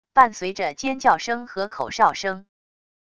伴随着尖叫声和口哨声wav音频